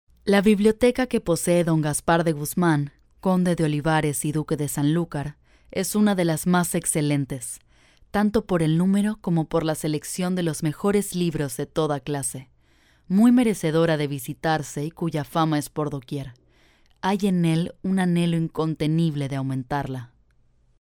locutora latina.
locutora espanol neutro. Latin American Spanish voice over